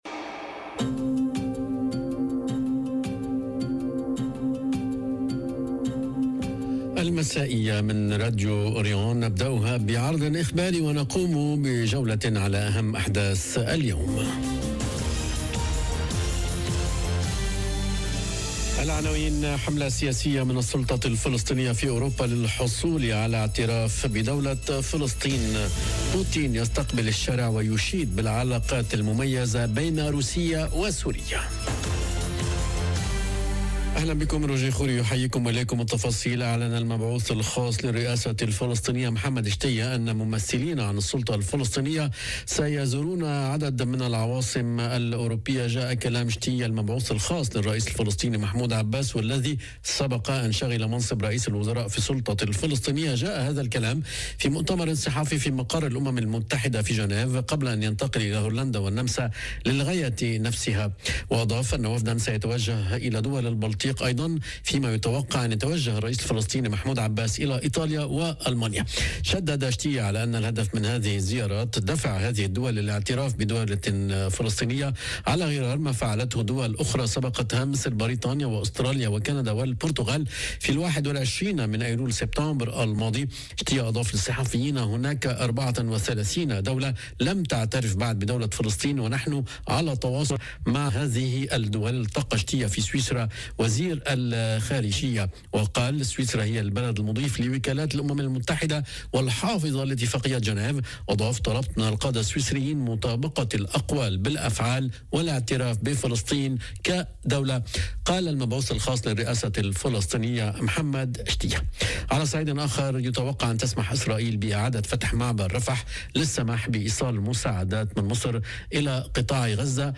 نشرة أخبار المساء: حملة سياسية من السلطة الفلسطينية في أوروبا للحصول على اعتراف بدولة فلسطين... وزيارة للشرع إلى موسكو - Radio ORIENT، إذاعة الشرق من باريس